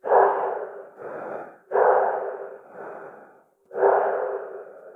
breath0gas.ogg